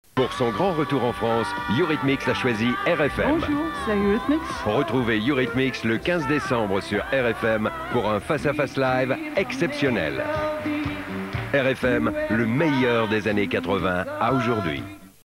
TV commercial (343 Kb)
RFM-TVadvert.mp3